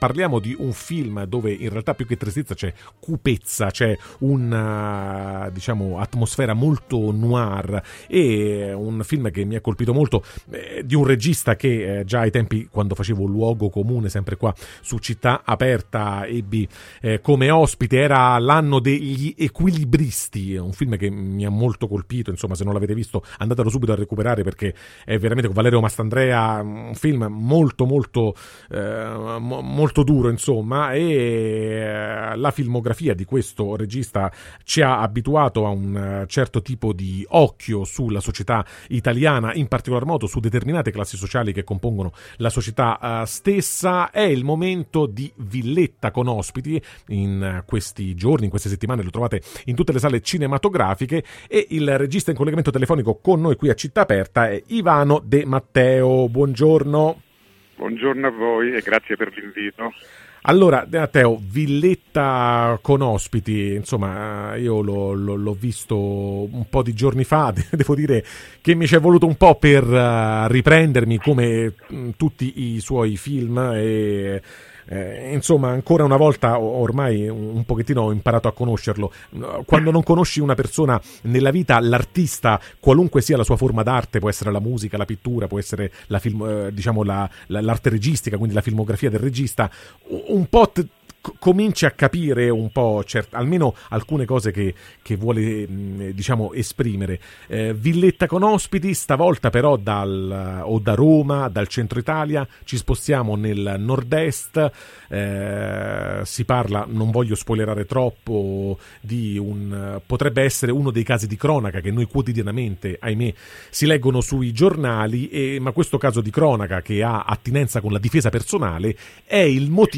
Intervista – “Villetta con ospiti” di Ivano De Matteo: un noir che obbliga a specchiarci | Radio Città Aperta